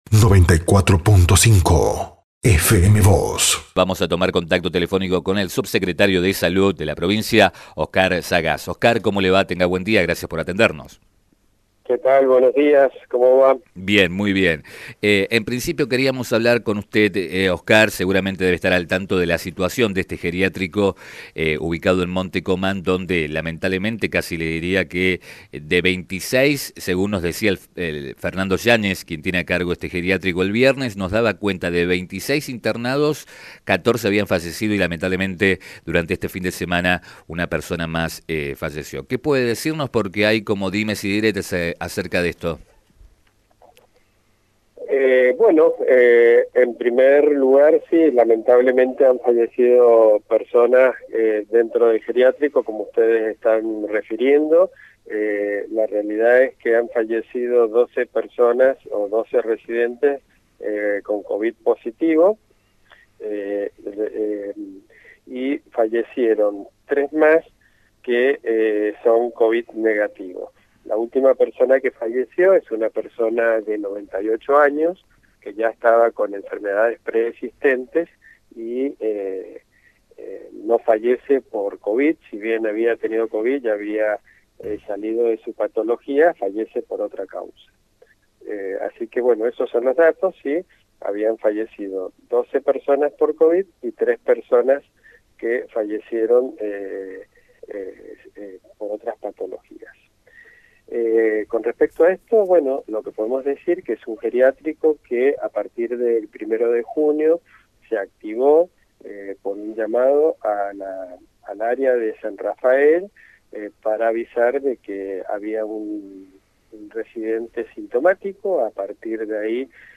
Al respecto, el subsecretario de Salud, Oscar Sagás, dijo a Diario San Rafael y FM Vos (94.5) que “lamentablemente han fallecido estas personas, de las cuales 12 tenían Covid positivo y 3 más Covid negativo.